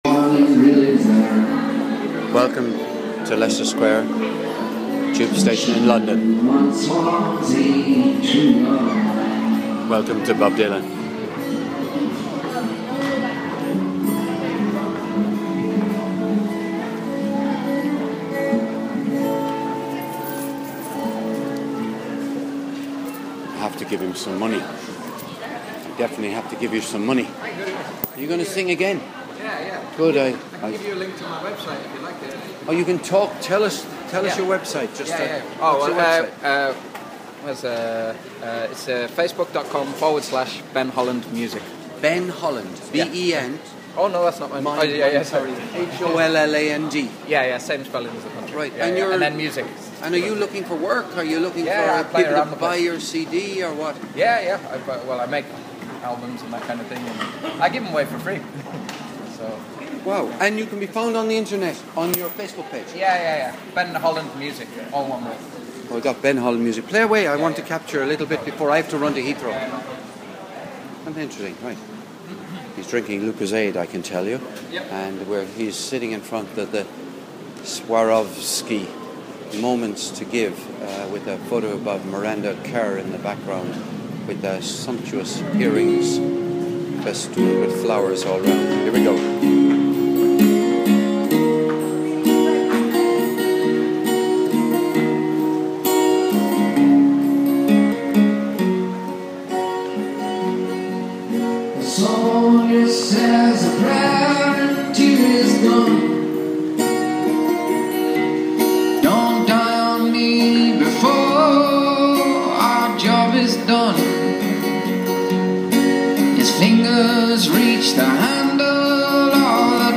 Busking in London Underground